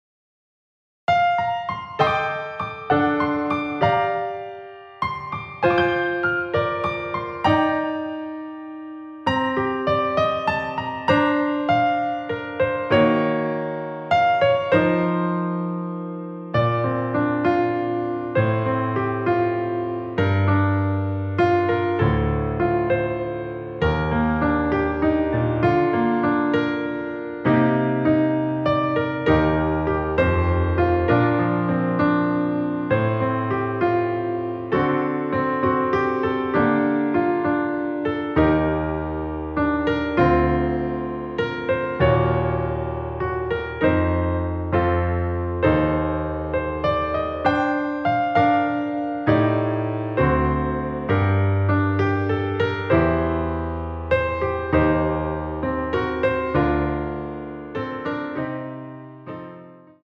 여성분이 부르실수 있는 키 입니다.
Bb
앞부분30초, 뒷부분30초씩 편집해서 올려 드리고 있습니다.